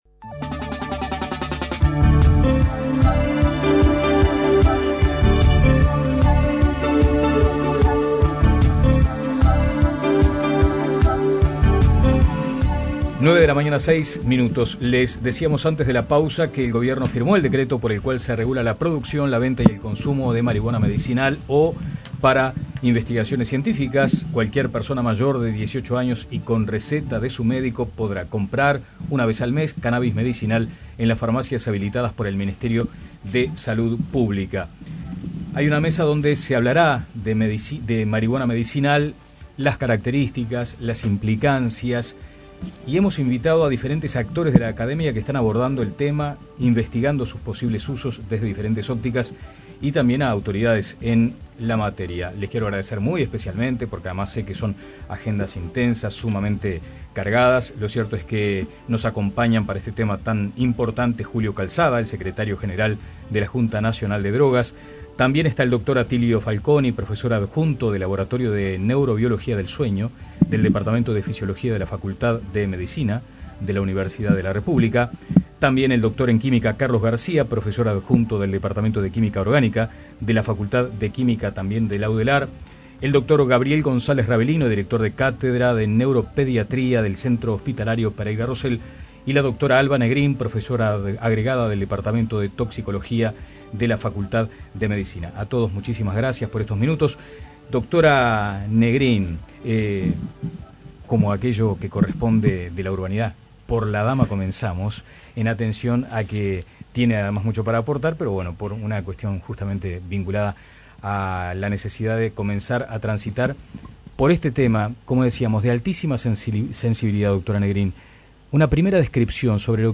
El gobierno firmó el decreto por el cual se regula la producción, la venta y el consumo de marihuana medicinal o para investigaciones científicas. En la mesa redonda se habló de las características e implicaciones del cannabis medicinal.